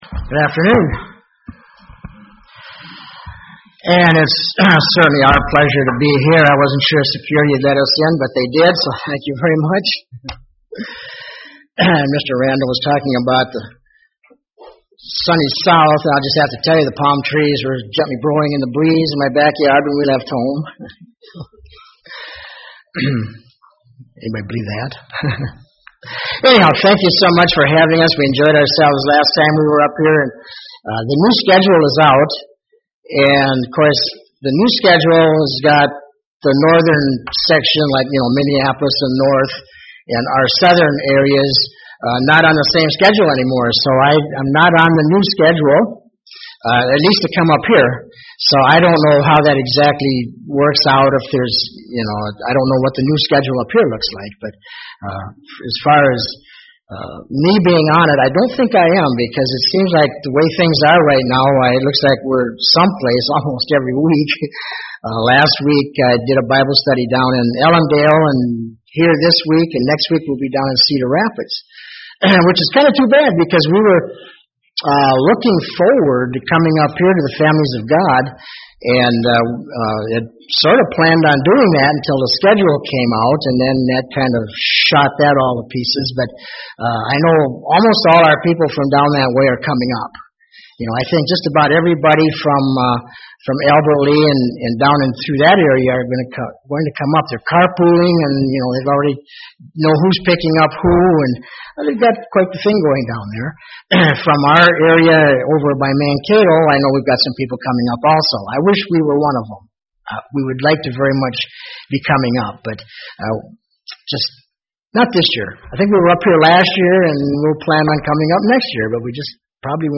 Given in Twin Cities, MN
Luke 4:18 UCG Sermon Studying the bible?